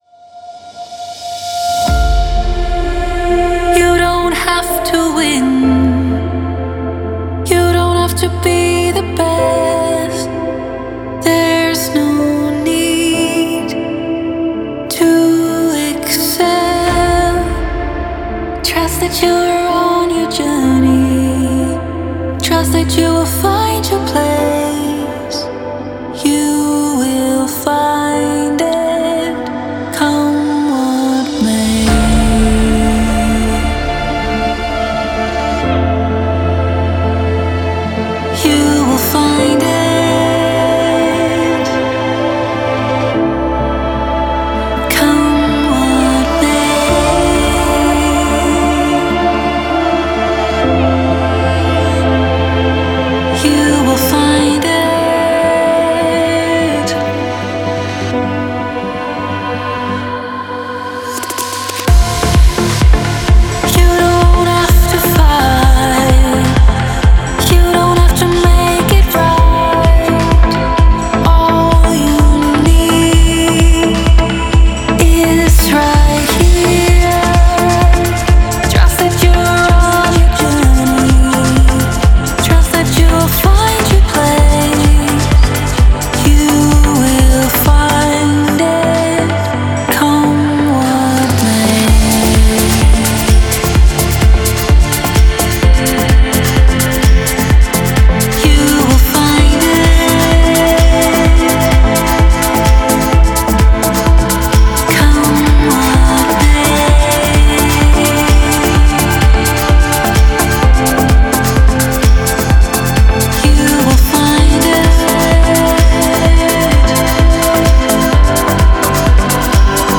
это яркая и uplifting трек в жанре электронной музыки